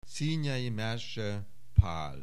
Aussprache Aussprache